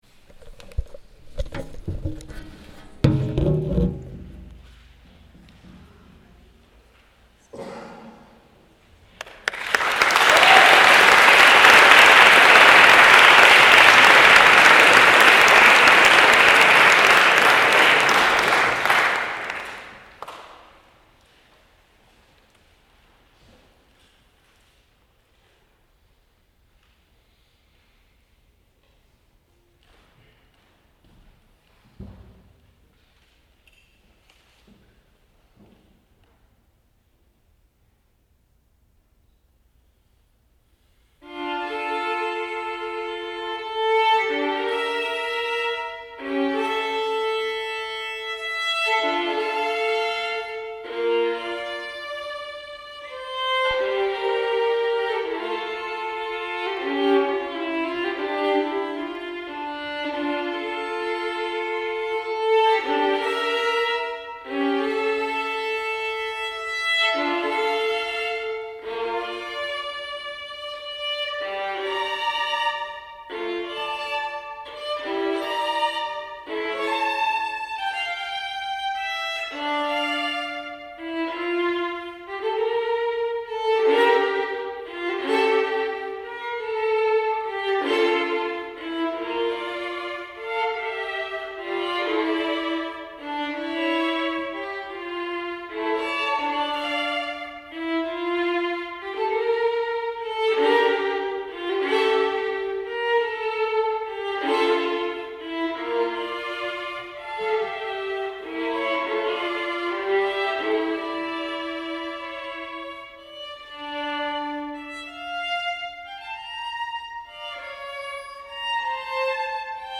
Emerging Artists concert July 4, 2013 | Green Mountain Chamber Music Festival
Chaconne
violin